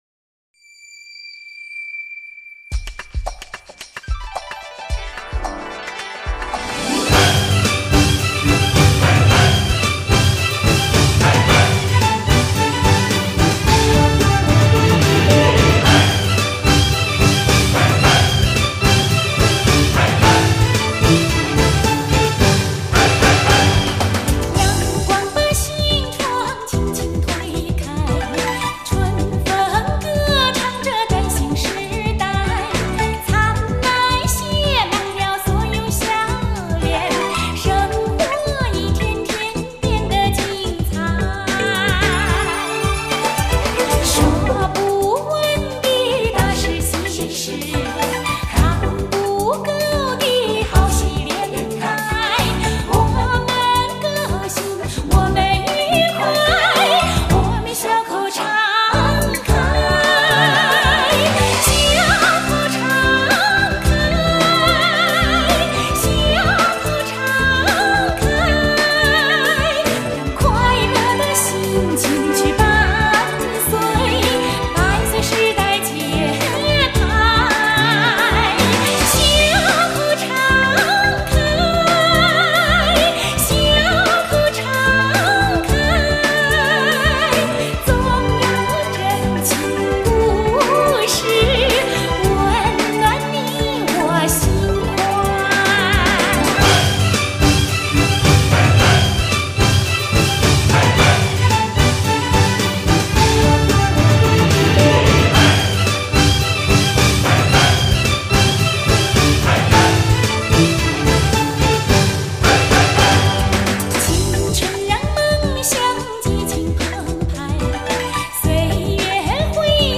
风格：China-Pop